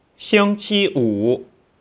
(Click on any Chinese character to hear it pronounced.